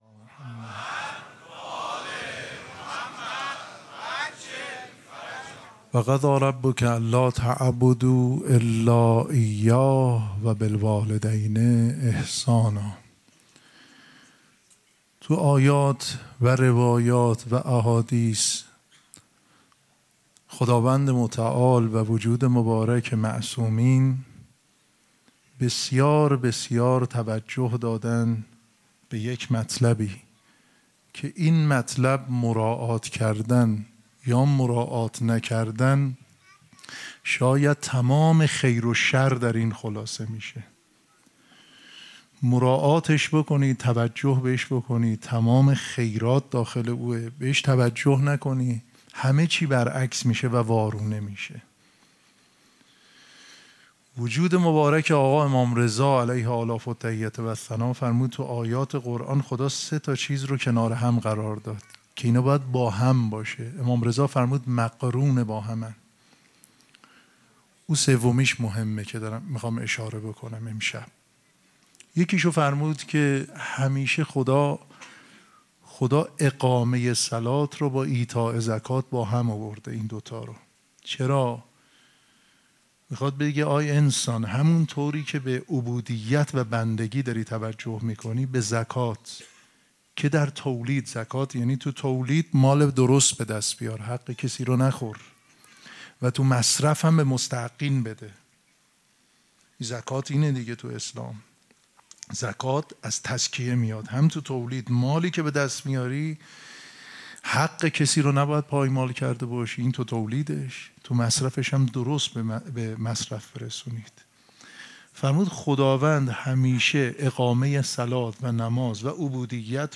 سخنرانی
شام وفات حضرت ام‌البنین(س)‌‌‌‌‌‌‌سه شنبه 5 دی ۱۴۰2 | ۱2 جمادی الثانی ۱۴۴5‌‌‌‌‌‌‌‌‌‌‌‌هیئت ریحانه الحسین سلام الله علیها